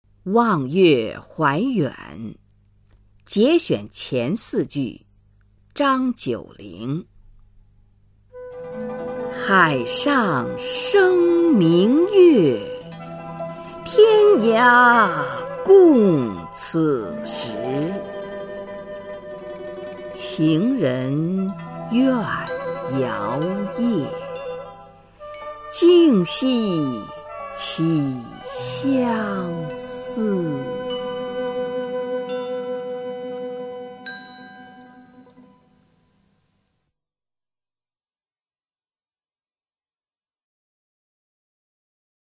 [隋唐诗词诵读]张九龄-望月怀远 配乐诗朗诵